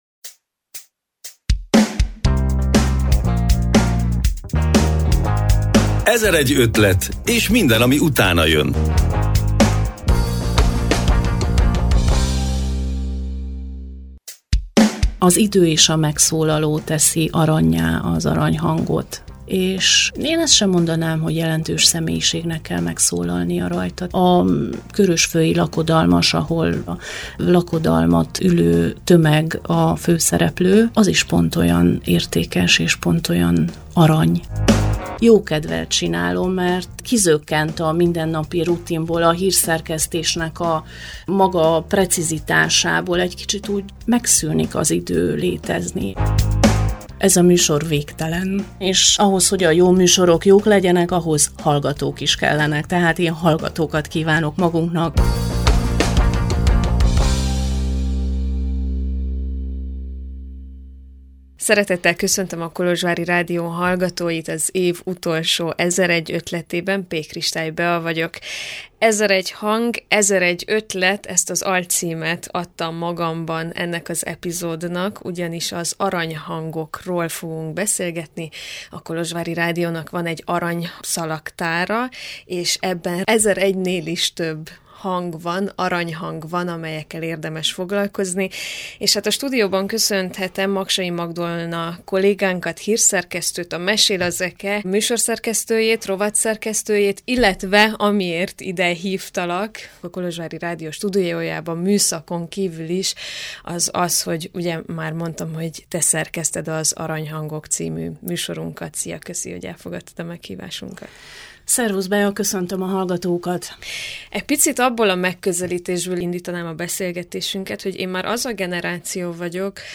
Mekkora elköteleződésről, szakmai kitartás szükséges ahhoz, hogy egy ilyen típusú munkát valaki 12 évek keresztül végezzen? A tavalyi év végén beszélgettünk erről, de a beszélgetés idén is ugyanannyira érvényes.